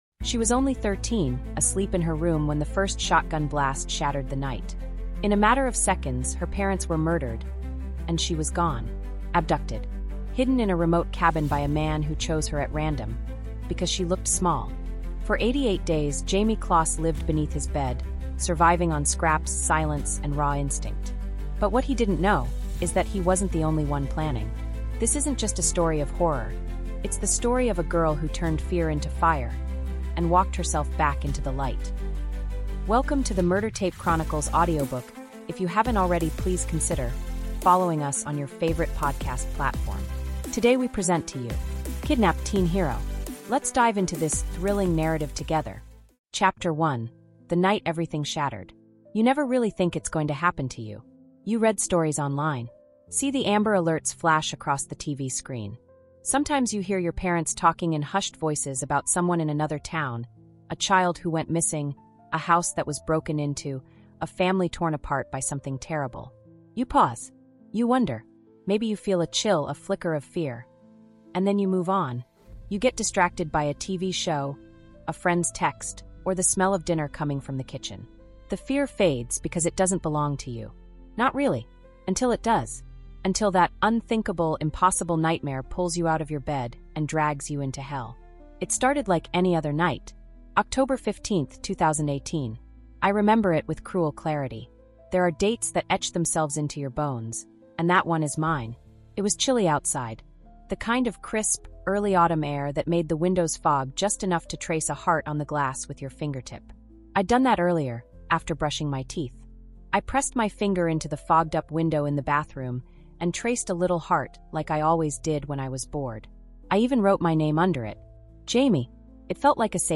Kidnapped Teen Hero | Audiobook